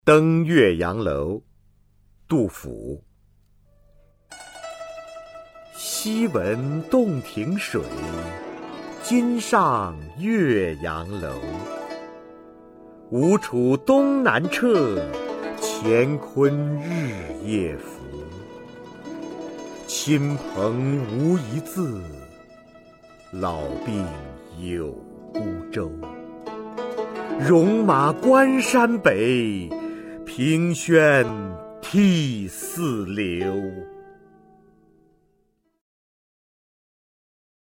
《登岳阳楼》原文和译文（含赏析、mp3朗读）